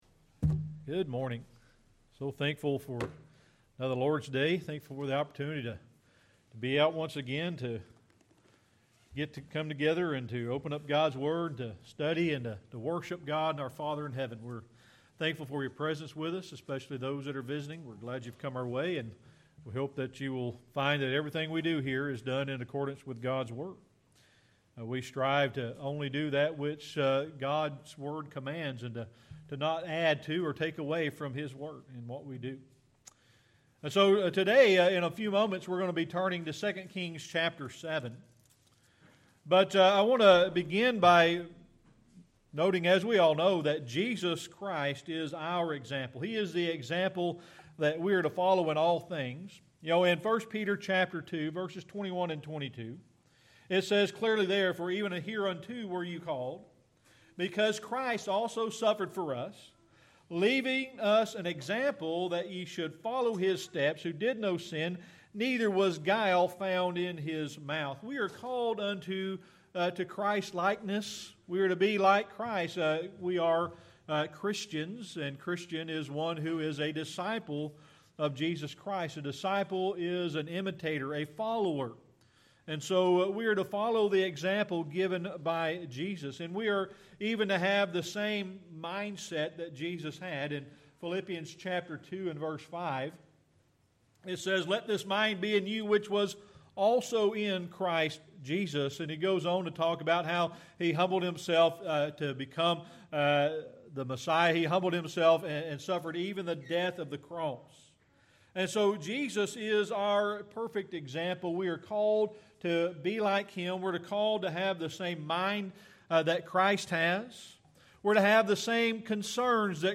Sermon Archives Passage